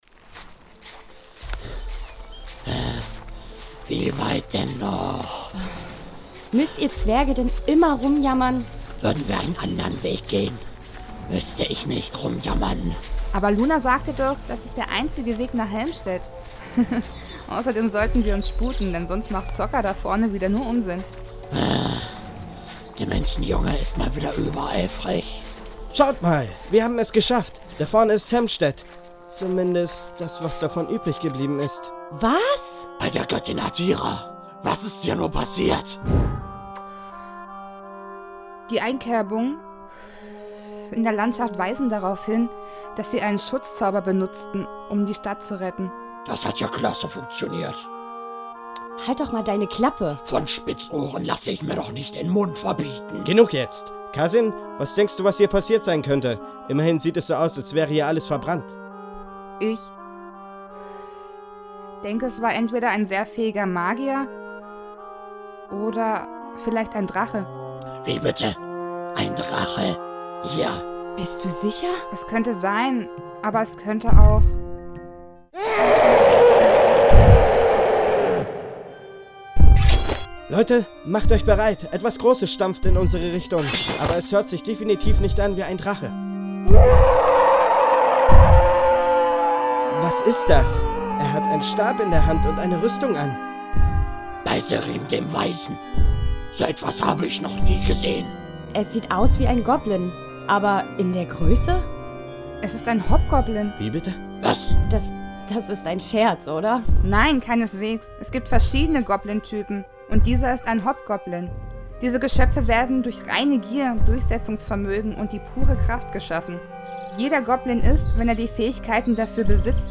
Meine eigenen selbstgeschriebenen Hörspiele/Szenen/Stücke